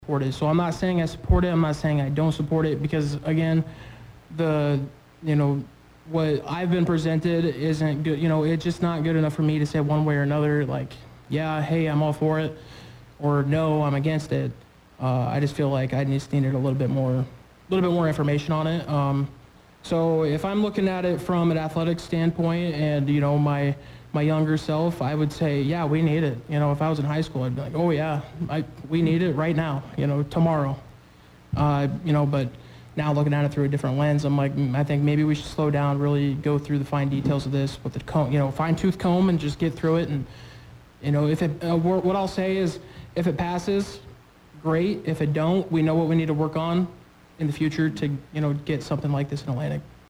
The bond issues highlighted Sunday’s Meredith Communications Meet Your Candidate’s Forum.